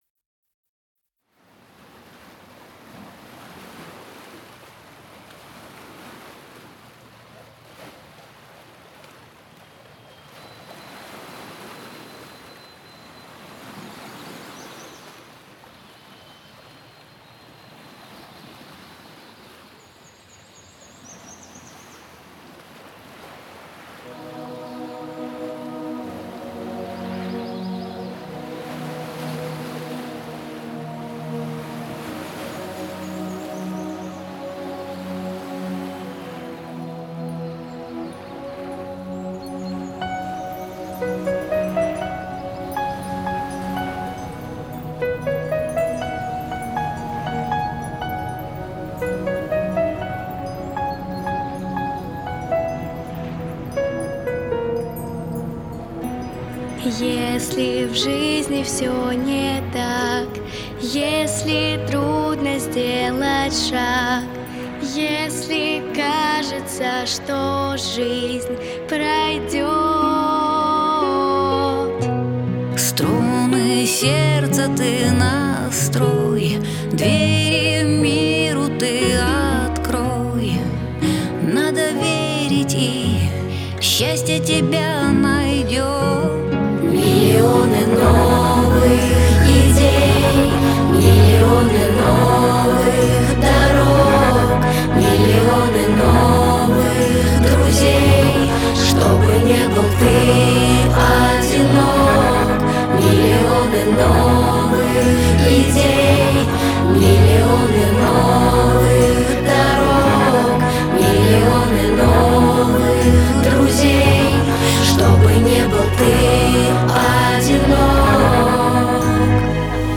Жанр: Pop
Стиль: Europop, Vocal